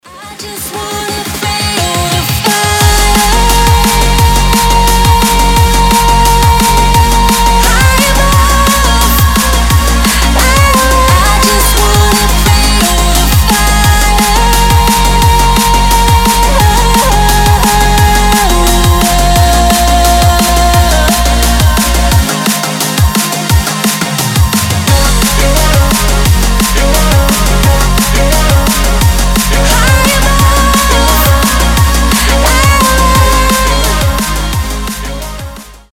Electronic
красивый женский голос
драм энд бейс